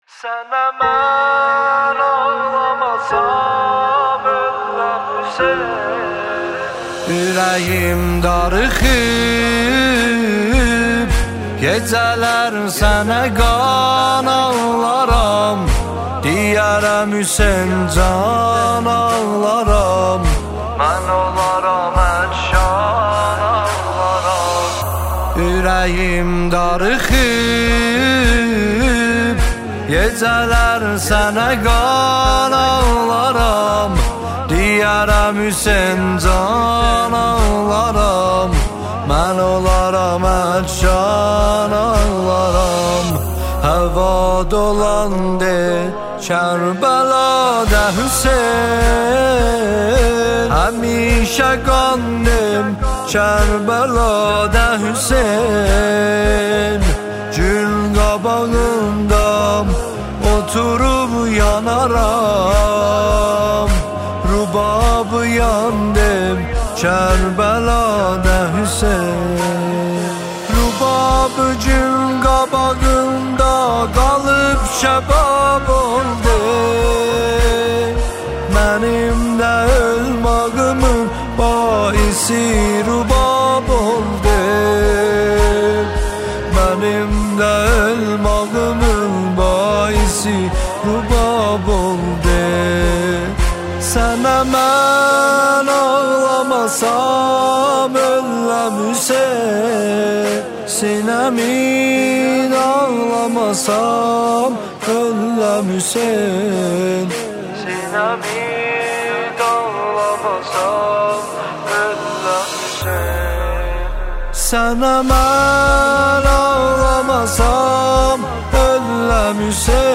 نماهنگ آذری دلنشین